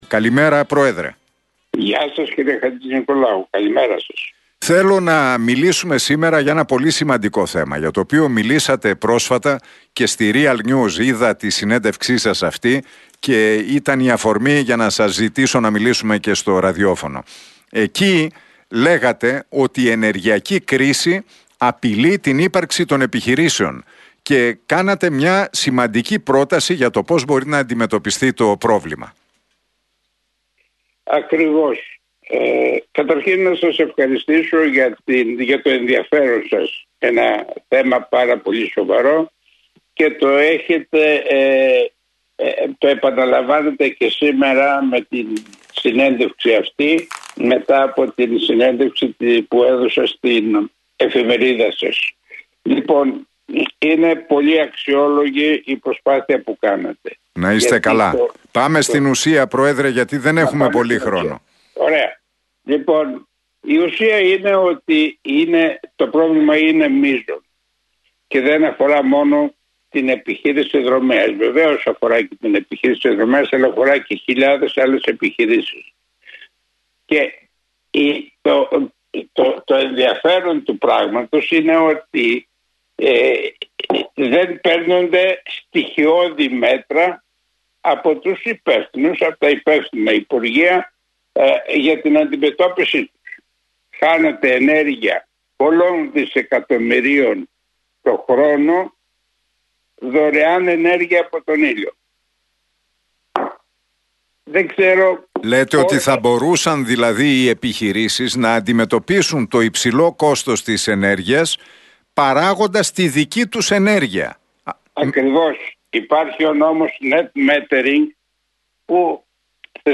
μιλώντας στην εκπομπή του Νίκου Χατζηνικολάου στον Realfm 97,8.